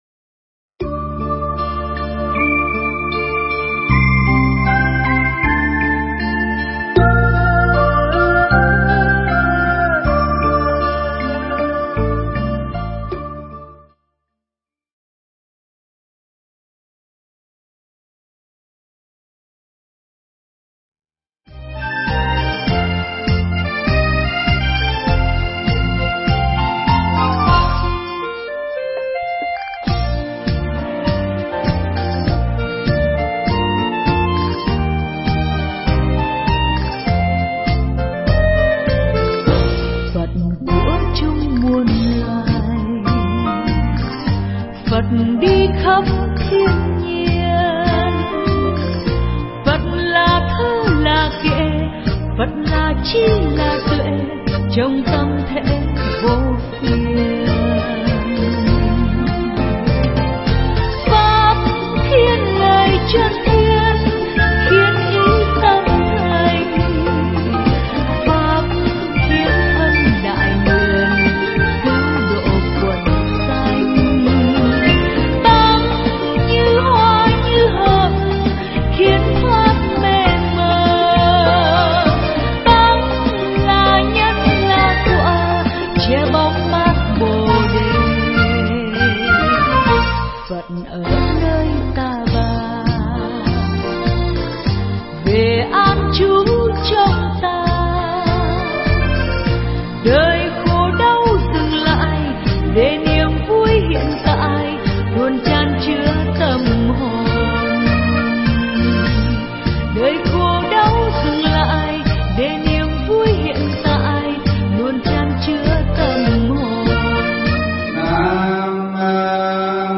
Nghe Mp3 thuyết pháp Sức Mạnh Của Người Tu